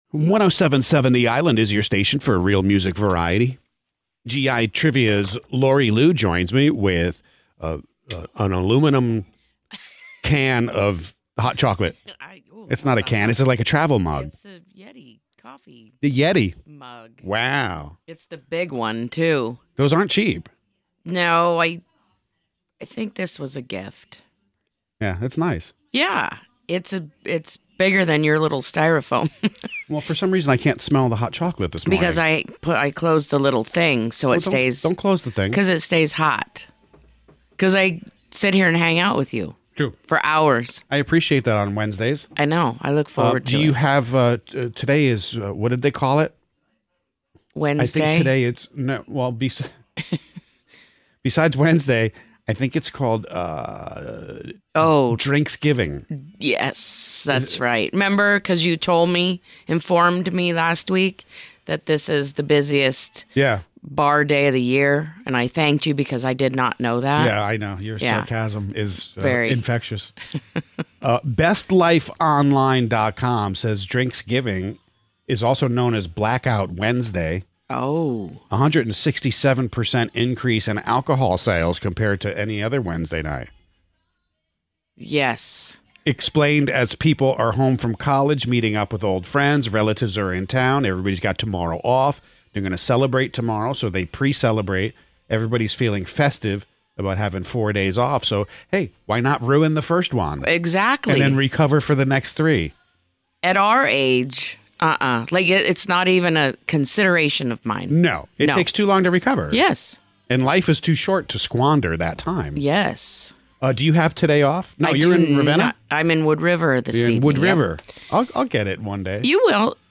Listen to a portion of The HUGE Broadcast by clicking below